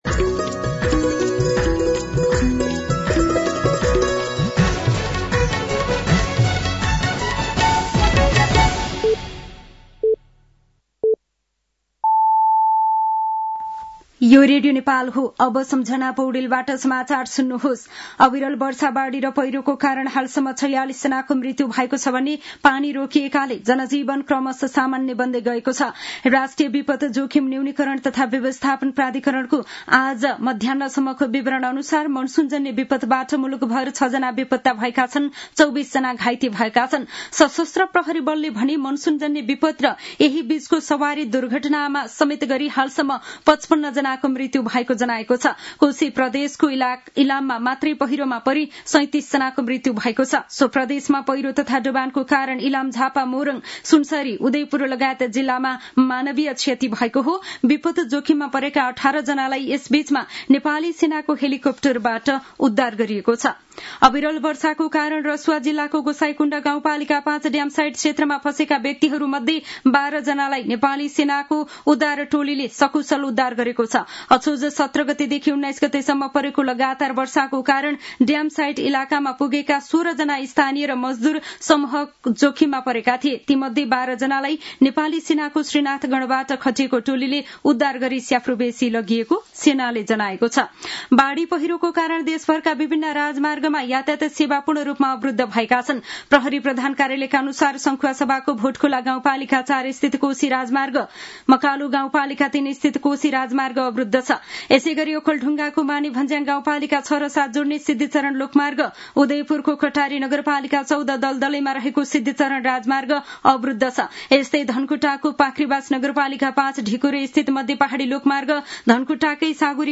साँझ ५ बजेको नेपाली समाचार : २० असोज , २०८२
5.-pm-nepali-news-1-1.mp3